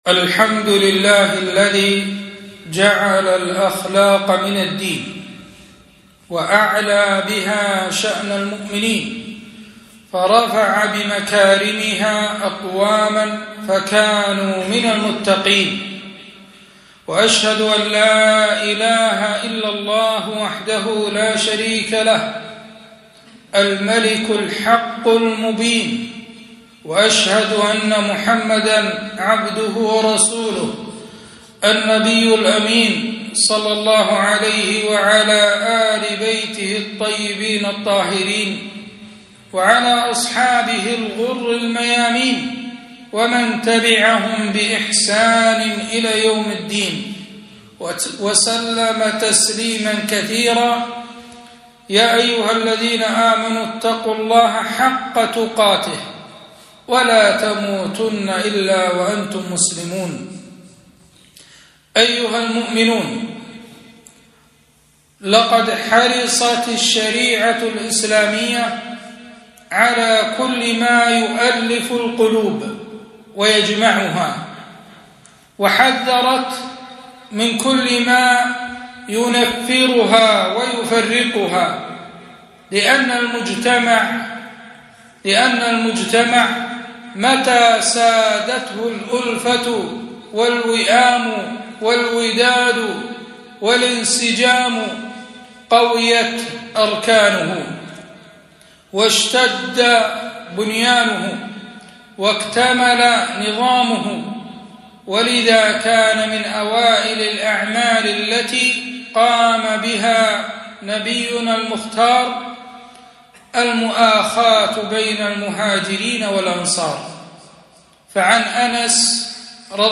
خطبة - أيها الناس أفشوا السلام